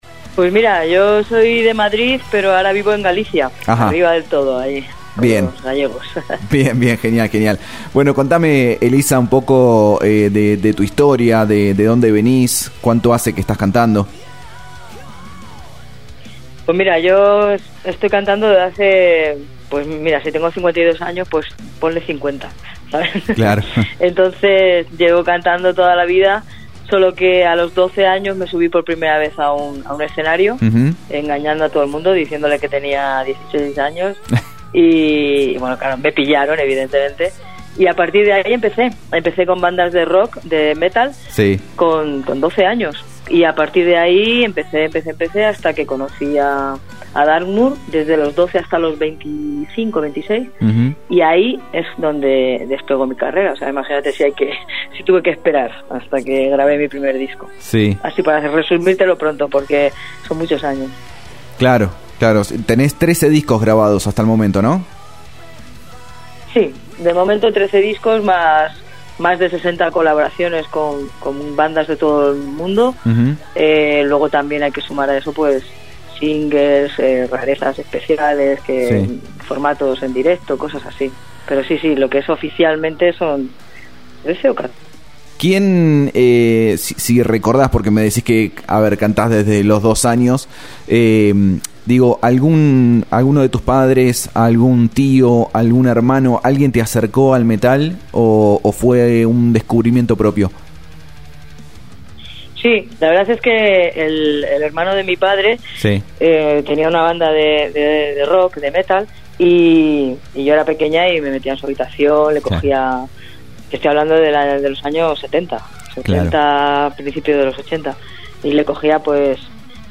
Notas y Entrevistas realizadas en Om Radio